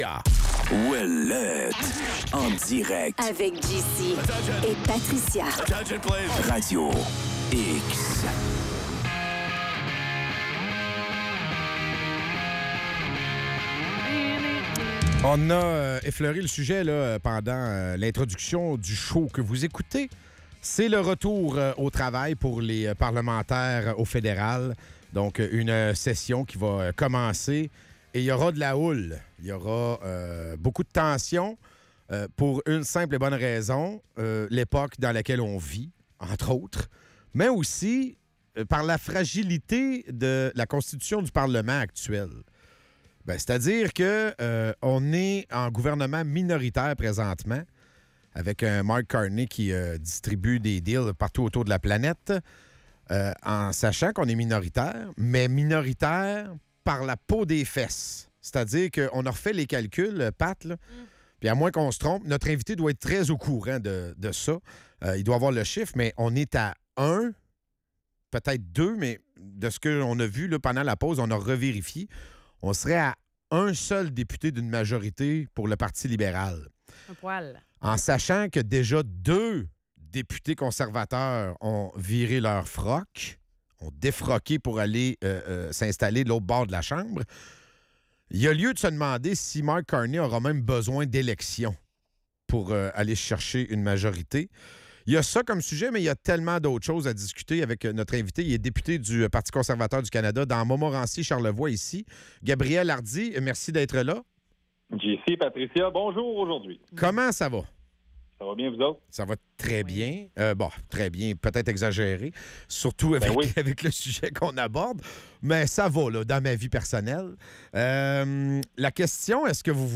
Le gouvernement minoritaire de Mark Carney est au cœur des discussions, avec des critiques sur sa gestion des finances publiques et des promesses non tenues. Gabriel Hardy, député conservateur, appelle à une plus grande responsabilité des élus et à une gestion plus efficace des fonds publics, tout en dénonçant les manœuvres politiques qui nuisent à la confiance des Canadiens.